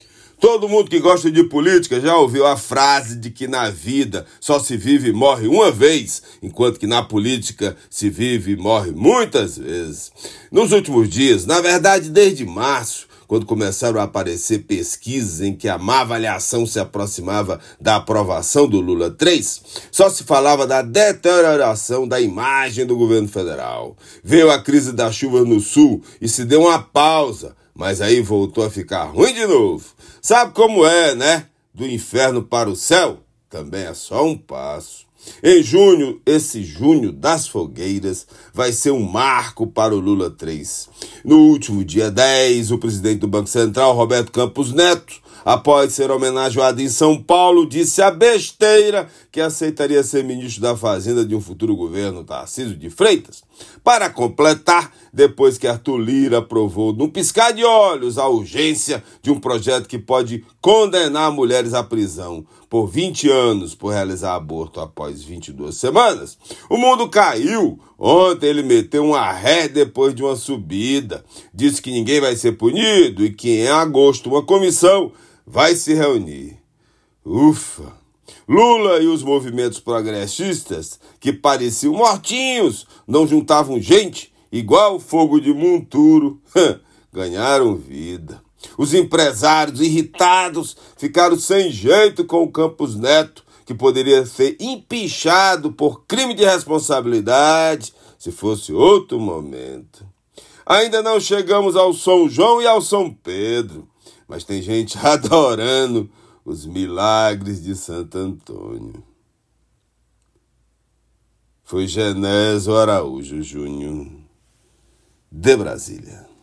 Comentário desta quarta-feira (19/06/24)
direto de Brasília.